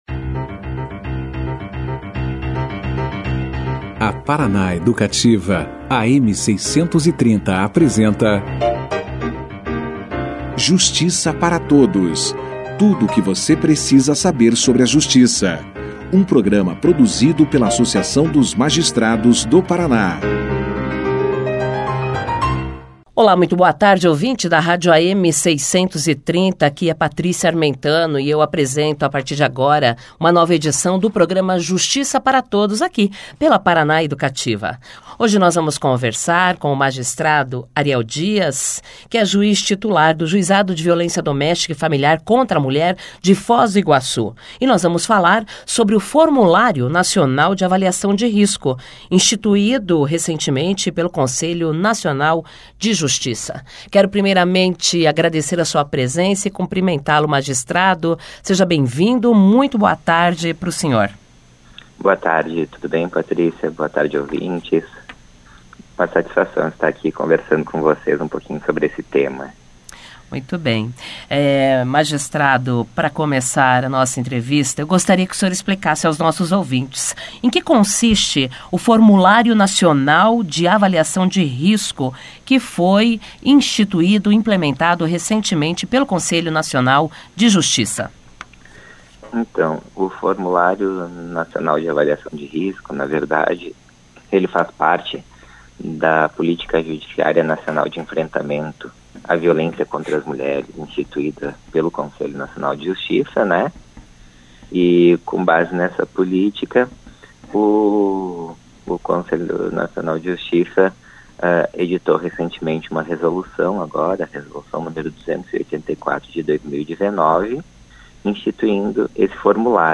O Programa Justiça Para Todos entrevistou nesta quarta-feira, 23 de outubro, o juiz Ariel Dias, magistrado titular do Juizado de Violência Doméstica e Familiar contra a Mulher, de Foz do Iguaçu, para falar sobre o Formulário Nacional de Avaliação de Risco instituído em junho deste ano pelo Conselho Nacional de Justiça.